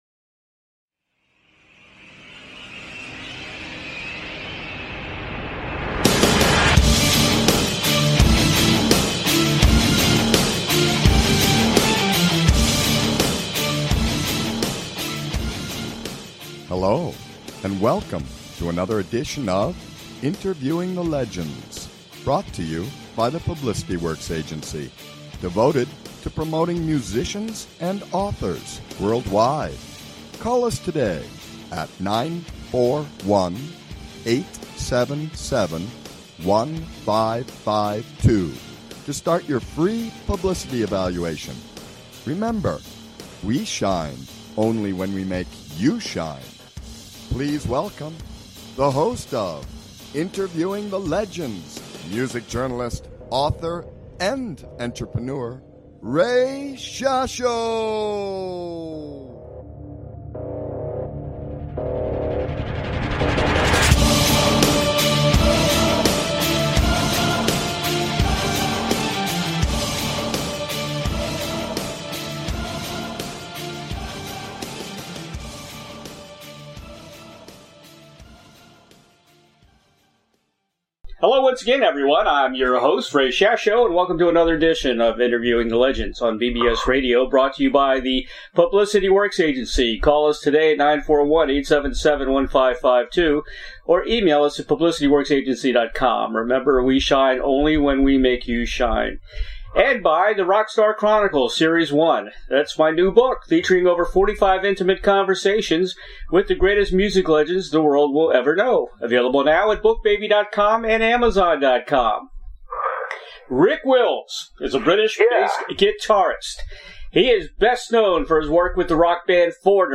Rick Wills legendary bassist with Foreigner. Peter Frampton. David Gilmour. Small Faces and Bad Company special guest